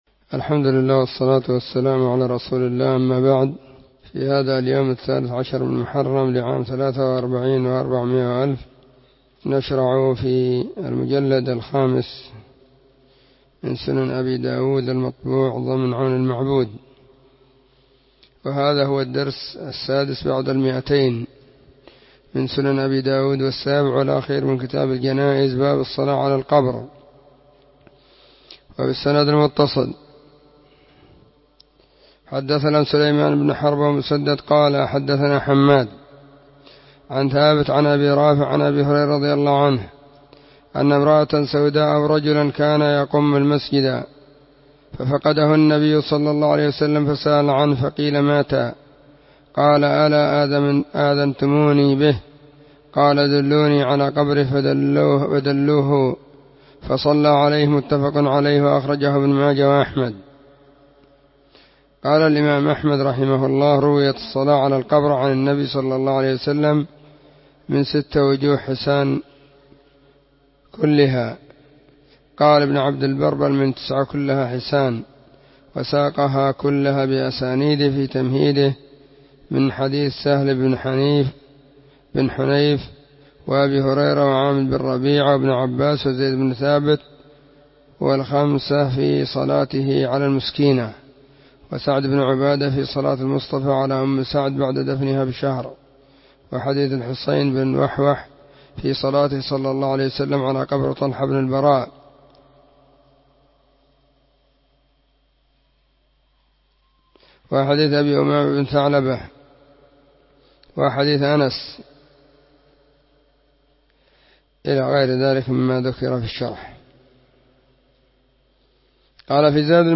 🕐 [بعد صلاة العصر في كل يوم الجمعة والسبت]